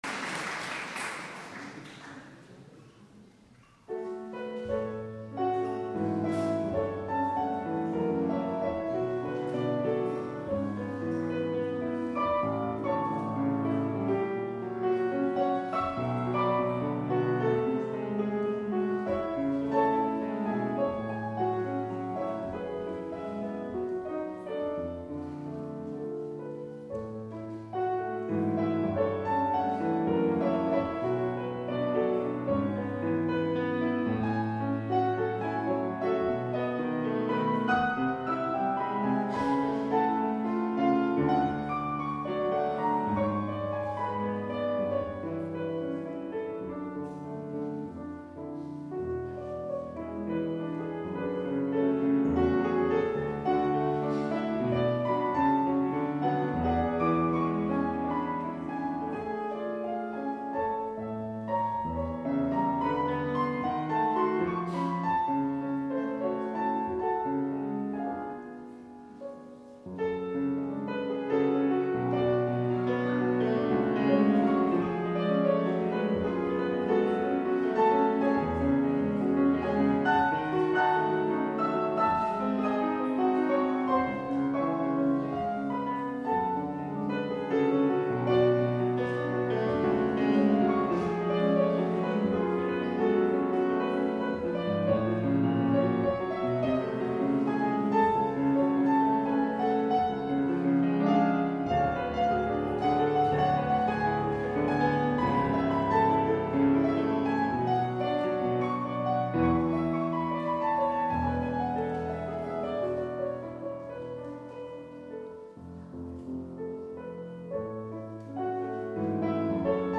PER PIANOFORTE SOLO